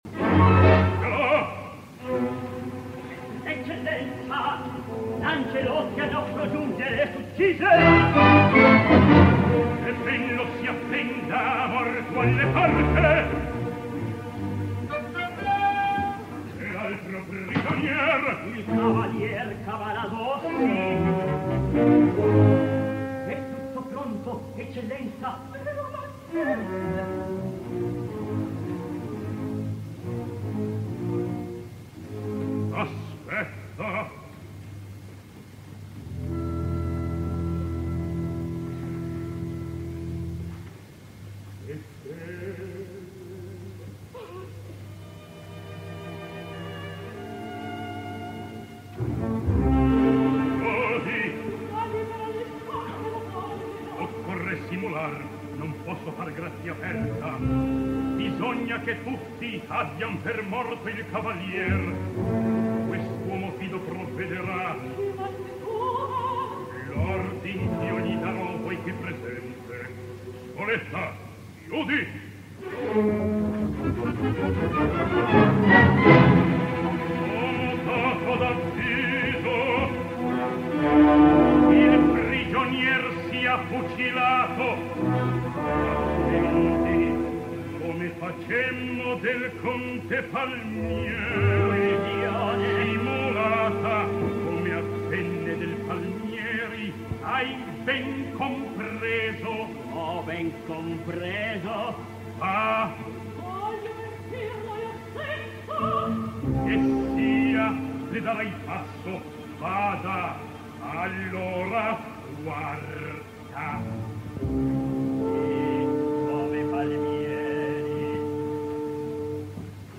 Eccellenza! L'Angelotti al nostro giungere s'uccise!, with Giuseppe Taddei and Renata Tebaldi
where he was a comprimario fixture at the Teatro Colón in Buenos Aires until as late as 1967.